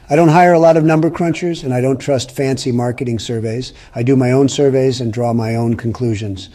Please note: The voices you hear in this description and the videos are AI generated.
Famous Personalities